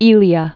(ēlē-ə) also Ve·li·a (vēlē-ə)